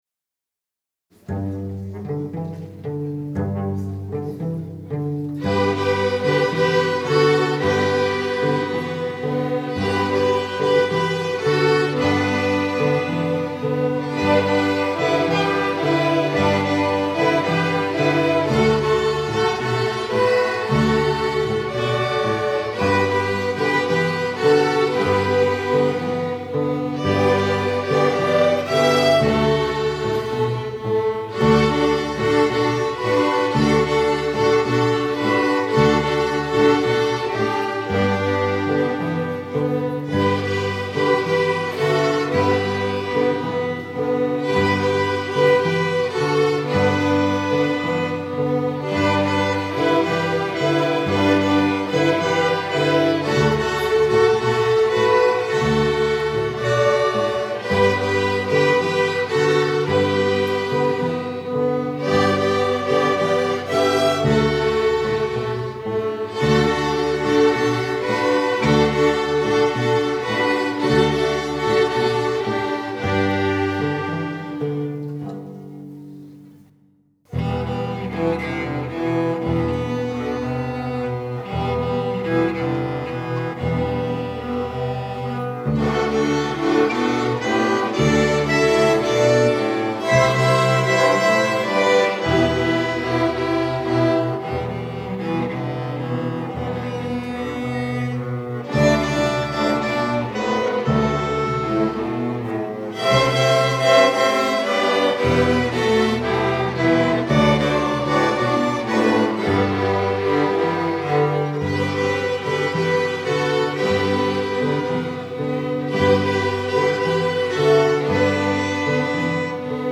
Dieser Intrumentalunterricht findet vormittags im normalen Klassenunterricht statt: Violin-, Viola-, Cello- und Kontrabass-Spieler lernen gemeinsam dieselben Stücke.
Streicherklasse (Hörprobe), Chor, Orchester oder Vokal Ensemble, die Möglichkeiten sich zu engagieren sind vielfältig.